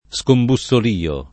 [ S kombu SS ol & o ]